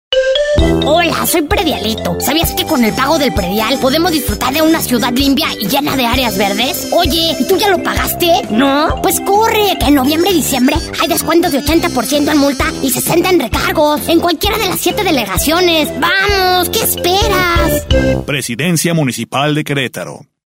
DEMOS
Spot Predialito.mp3